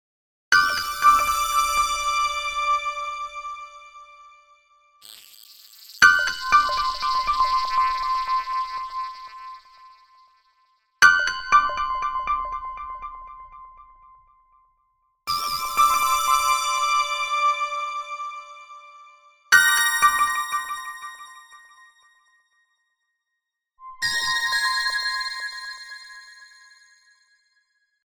mp3 cell phone ringtone